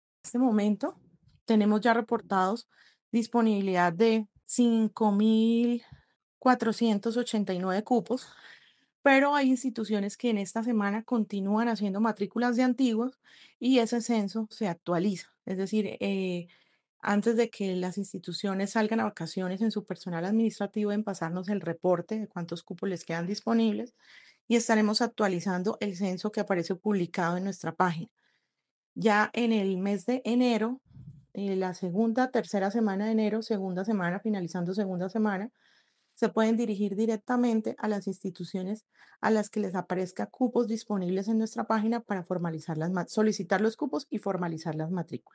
Elsa Encinales, secretaria de Educación,